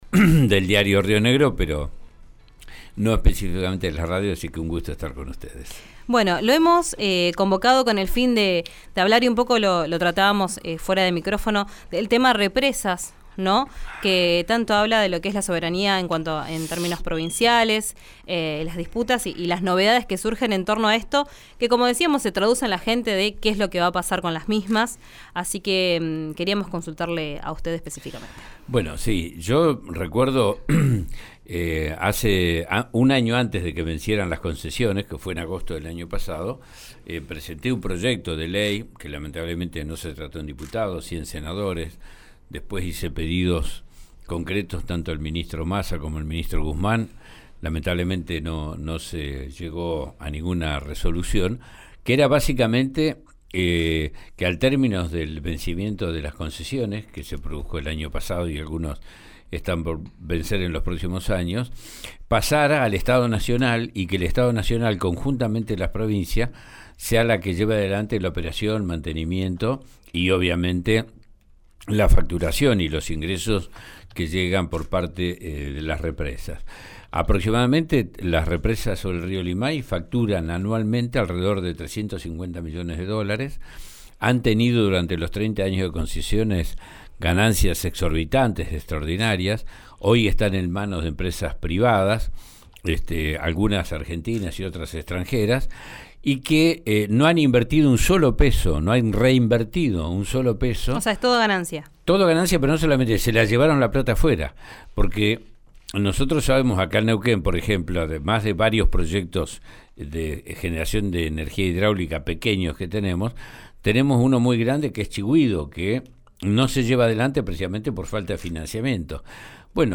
Escuchá a Oscar Parrilli, senador de Unión por la Patria, en RÍO NEGRO RADIO:
En una entrevista exclusiva con RÍO NEGRO RADIO, el histórico dirigente del PJ habló también de la denuncia que hizo Fabiola Yáñez por violencia contra Alberto Fernández y aseguró que Cristina Kirchner «no sabía» ni estaba al tanto de su vida privada.